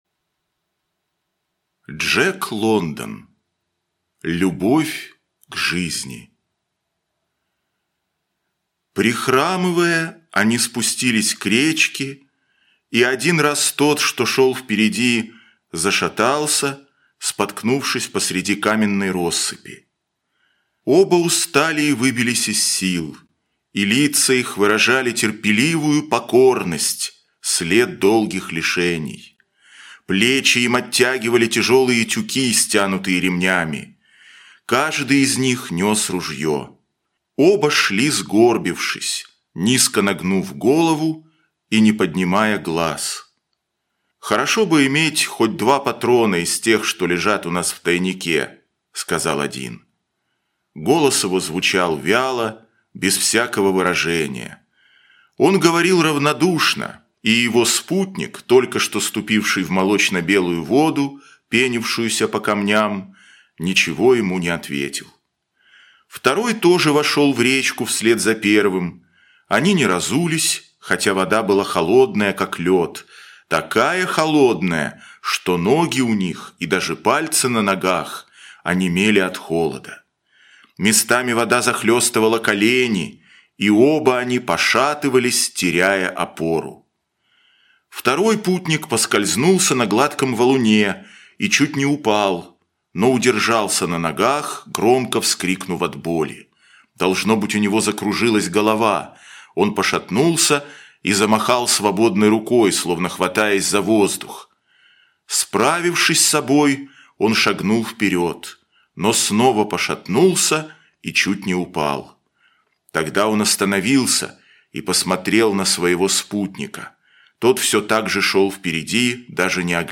Любовь к жизни - аудио рассказ Лондона - слушать онлайн